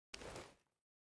Divergent / mods / Spas-12 Reanimation / gamedata / sounds / weapons / spas / holster.ogg
holster.ogg